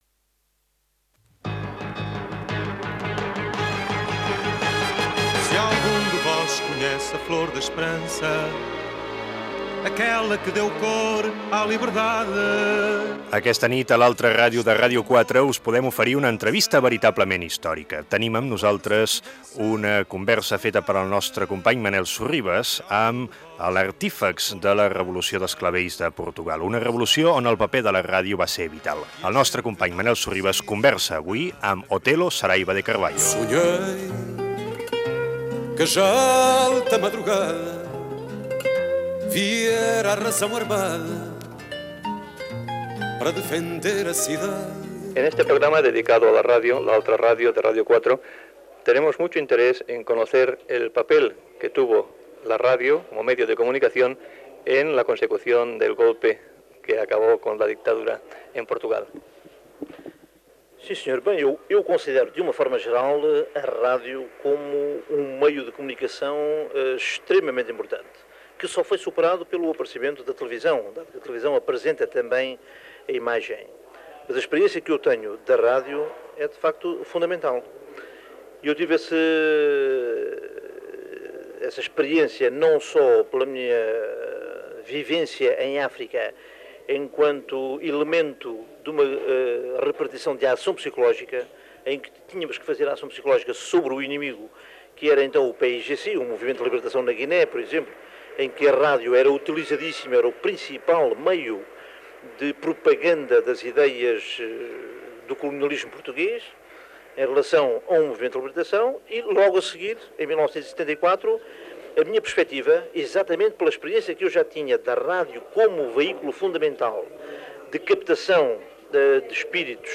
Divulgació
Entrevista feta a Arenys de Mar el dia 16 de setembre de 1989.